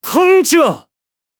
Fish Speech：無料で使える音声AIで「こんにちは！」と言ってもらった
オリジナルの話者を作ってみました。